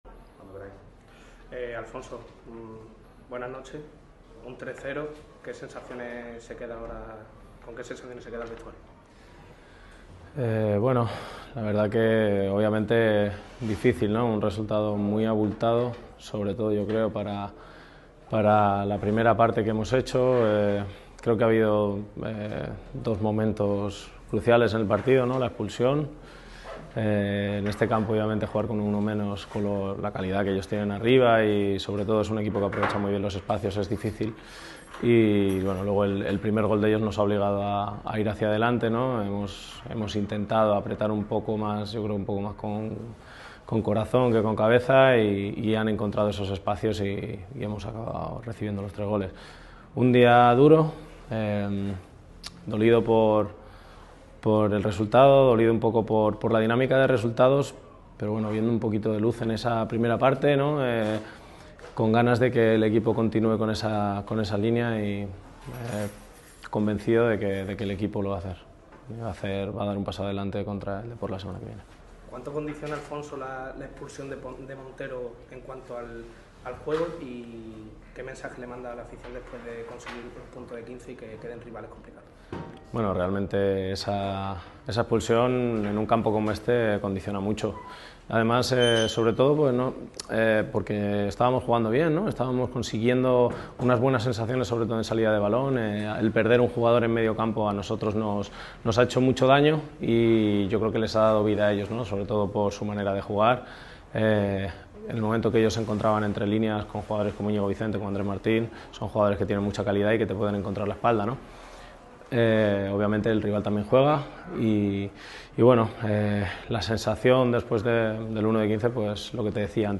Aún así, el capitán malaguista sacó coraje para dar unas palabras en zona mixta y se mostró con confianza pese al duro calendario que se le avecina a los de Martiricos.